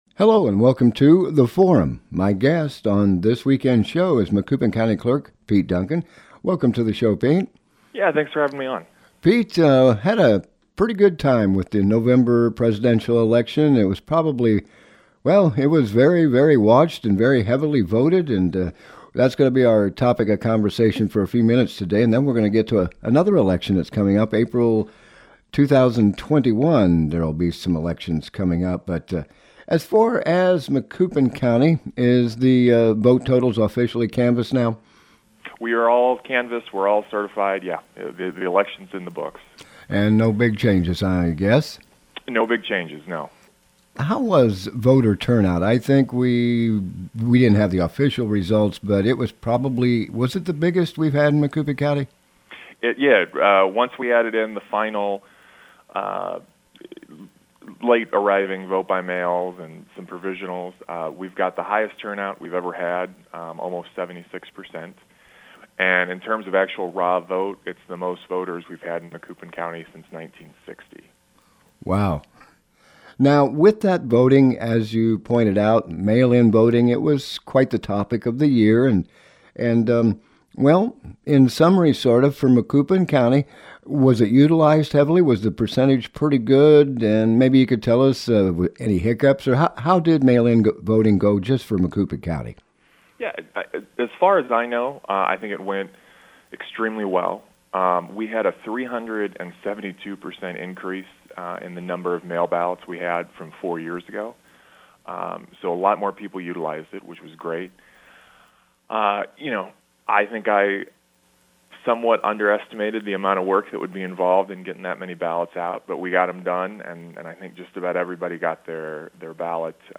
Guest: Pete Duncan - Macoupin County Clerk